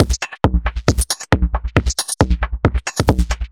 Index of /musicradar/uk-garage-samples/136bpm Lines n Loops/Beats
GA_BeatFilterB136-08.wav